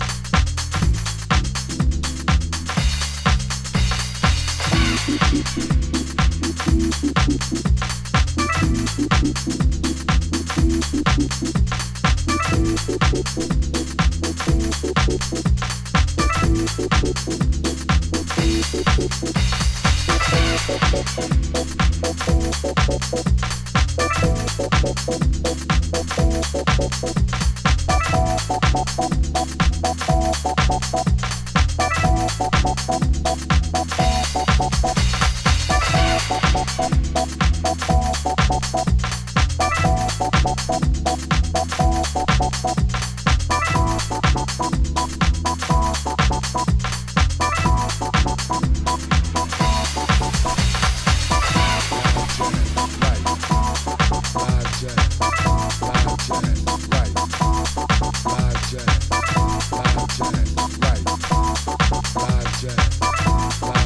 少し捻りの効いたGarage Houseサウンド。
暖かいシンセが心地良いDeep House